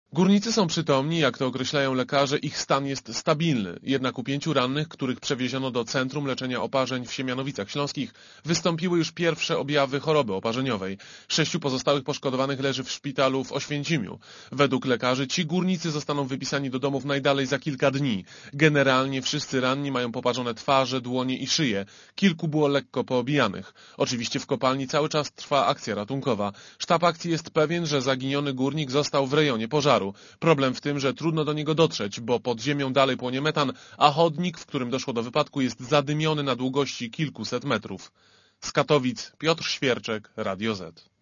Relacja reportera Radia Zet (160Kb)